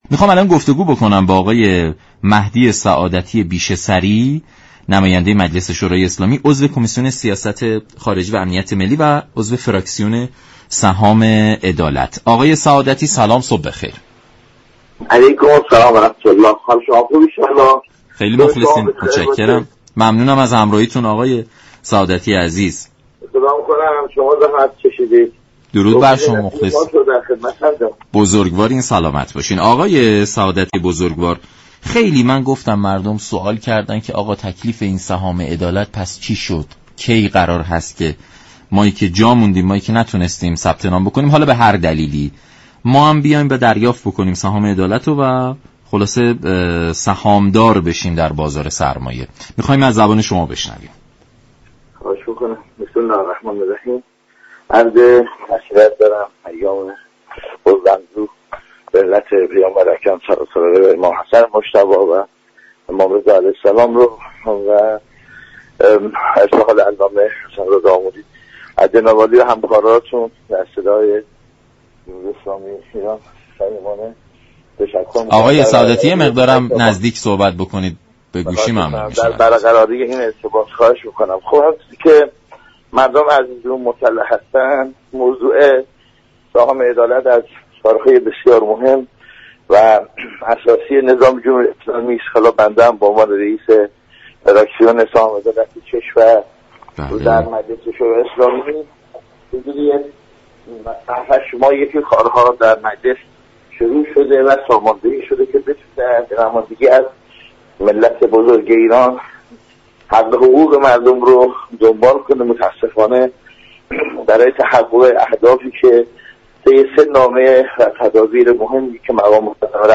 دریافت فایل به گزارش شبكه رادیویی ایران، مهدی سعادتی بیشه سری رئیس فراكسیون سهام عدالت مجلس در برنامه «سلام صبح بخیر» رادیو ایران به بحث سهام عدالت و جاماندگان آن پرداخت و گفت: در حال حاضر سهام عدالت برای 2 میلیون فوتی در كشور تعیین و تكلیف نشده كه در صورت تامین منابع ساماندهی خواهد شد.